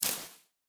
Minecraft Version Minecraft Version 25w18a Latest Release | Latest Snapshot 25w18a / assets / minecraft / sounds / block / cobweb / break1.ogg Compare With Compare With Latest Release | Latest Snapshot
break1.ogg